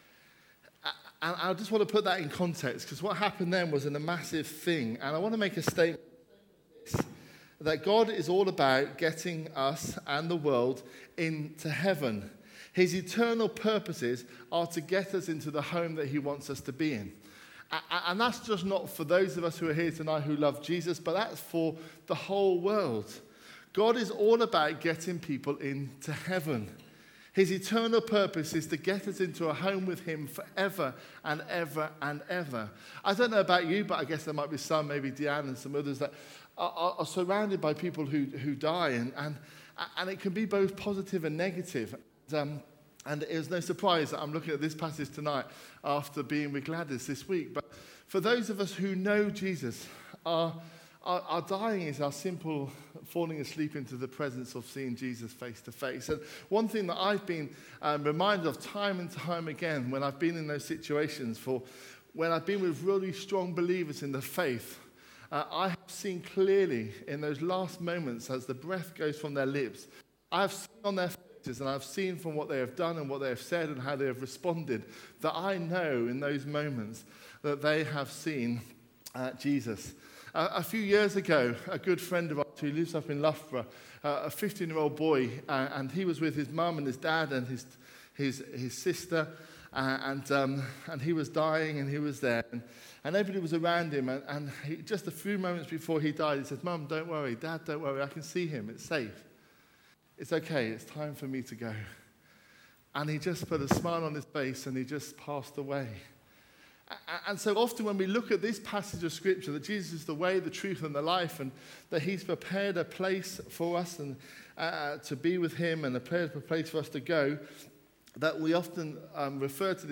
A message from the series "Jesus said 'I AM'."